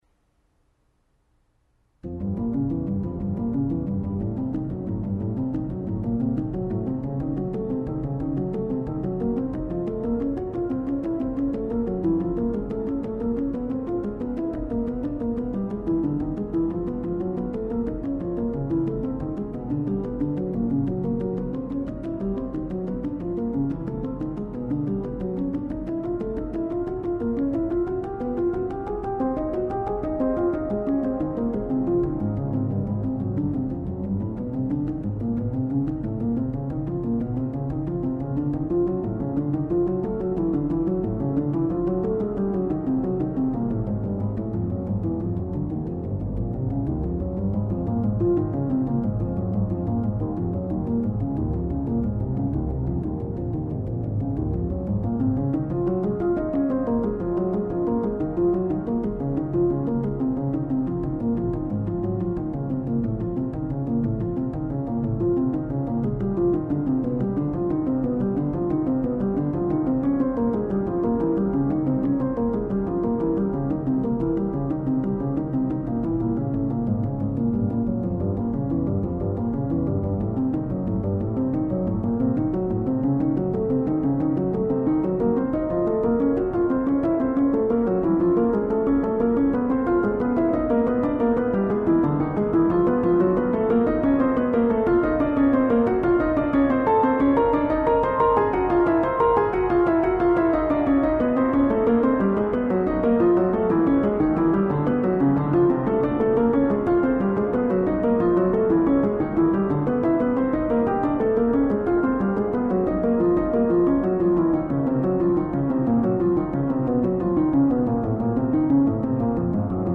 Jene Sychronläufe in der merkwürdig anmutenden Kombination aus Hochgeschwindigkeit und Super-Pianissimo, über die berühmte Zeitgenossen und Kollegen des Komponisten verständnislos die Köpfe geschüttelt hatten, schienen ihm jeder Beschreibung zu spotten und gleichsam jedem automatischen oder unbewussten Deutungsversuch die Zunge herauszustrecken.